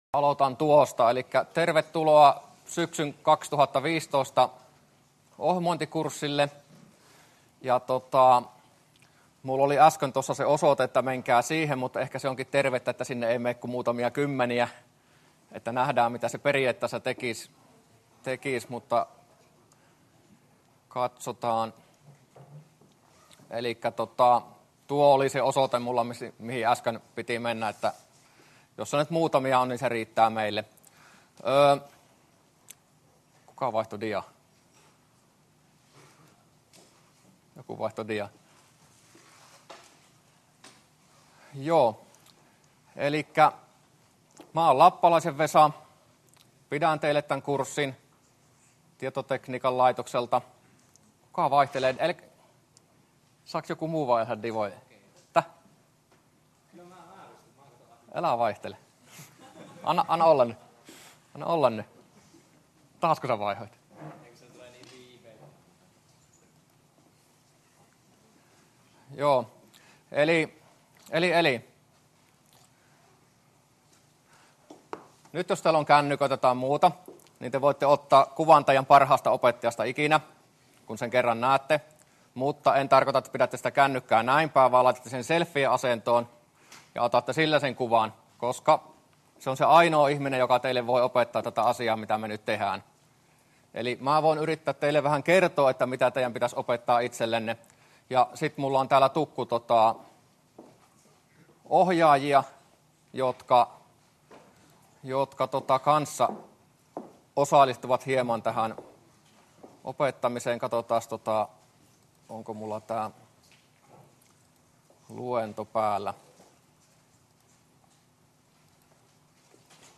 luento01